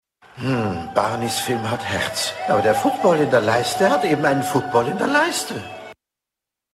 Er war ein deutscher Schauspieler und Synchronsprecher.
H Ö R B E I S P I E L E – in der finalen Tonmischung: